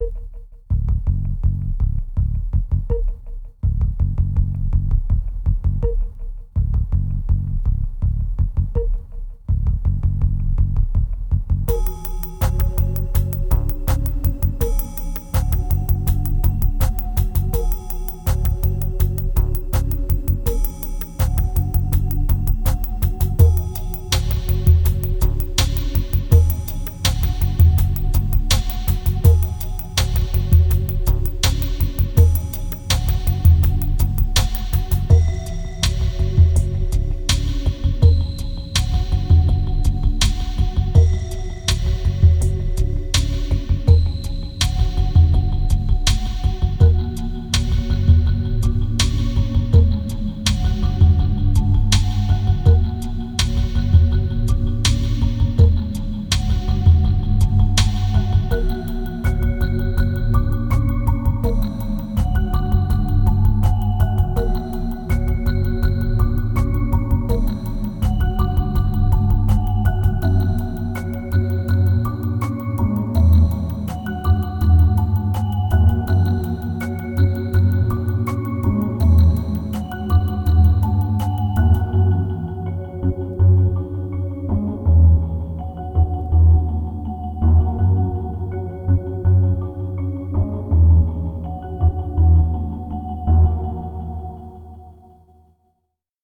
2218📈 - 3%🤔 - 82BPM🔊 - 2009-12-12📅 - -169🌟